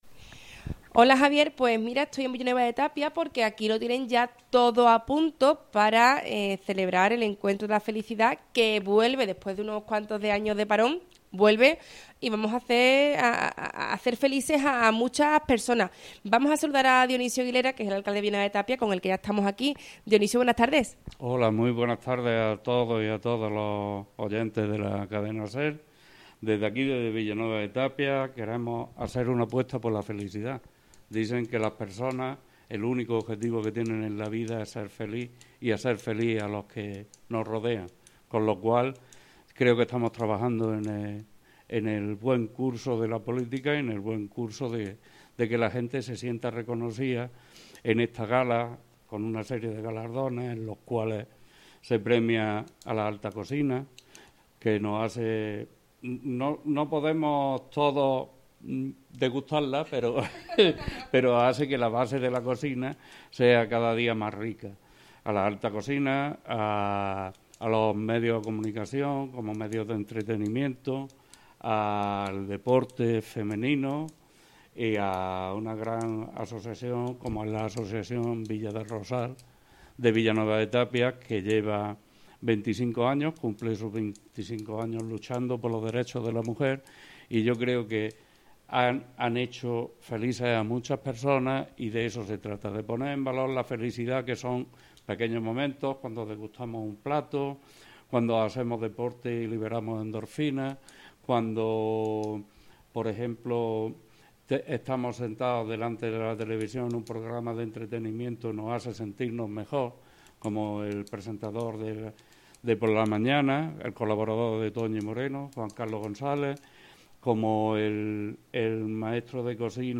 Directo Villanueva de Tapia. III Encuentro de la Felicidad. - Andalucía Centro